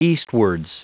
Prononciation du mot eastwards en anglais (fichier audio)
Prononciation du mot : eastwards